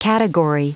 Prononciation du mot category en anglais (fichier audio)